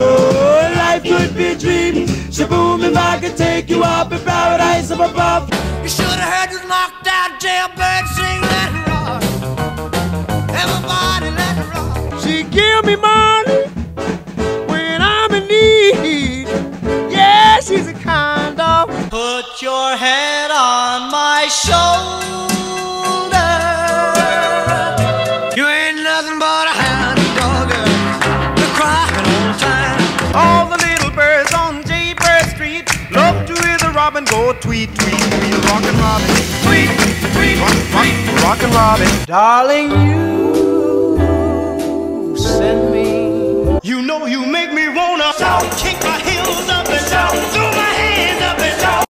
1950s Music Editions/1950s Music Categories. sound effects free download